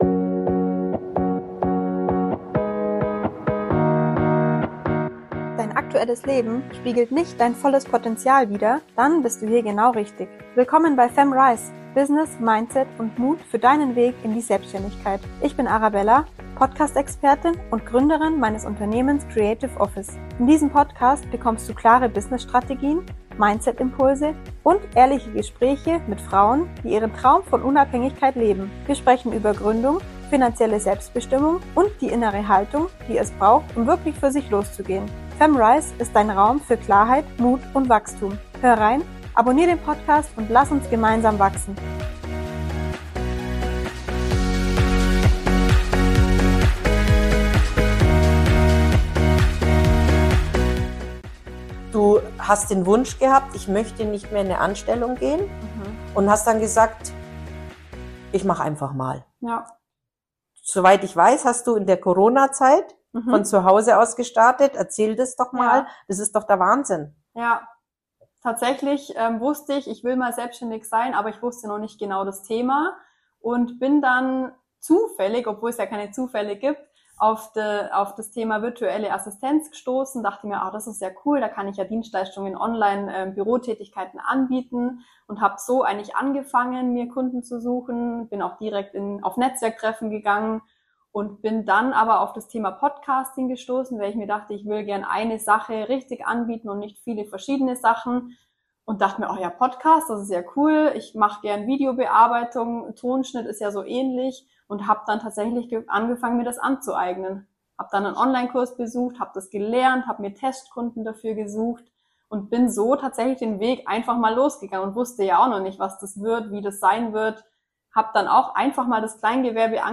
Interviewgast: